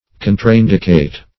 Contraindicate \Con"tra*in*"di*cate\, v. t. [imp. & p. p.